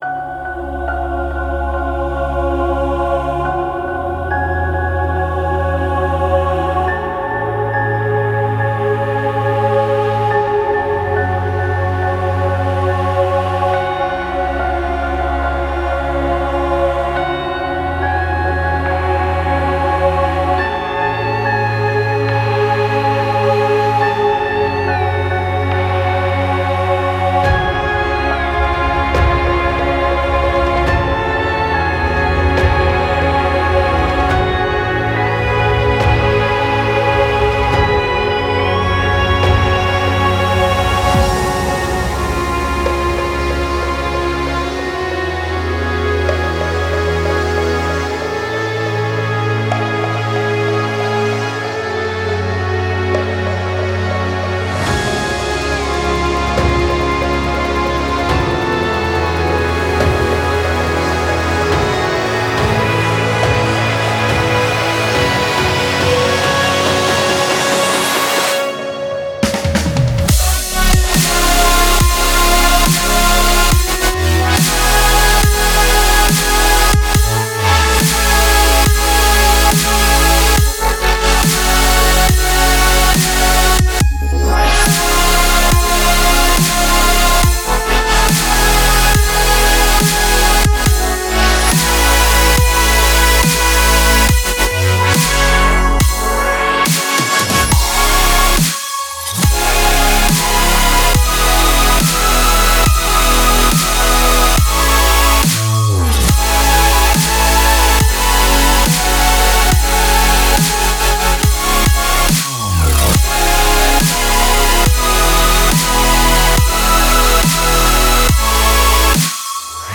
Melodic Dubstep, Epic, Restless, Dark, Suspense